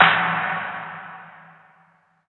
Dark Days Snare.wav